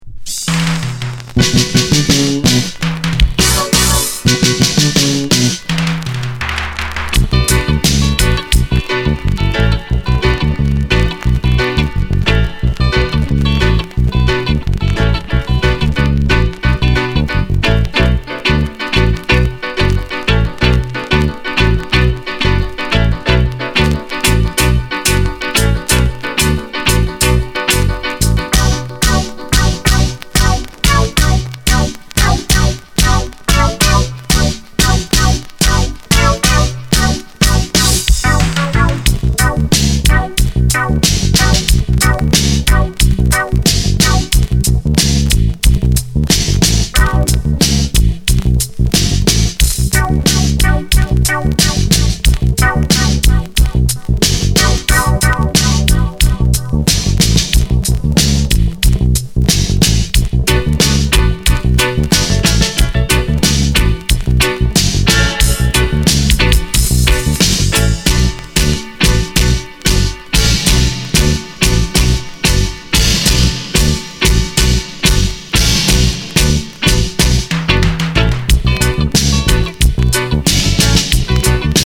Genre: Reggae/Dance hall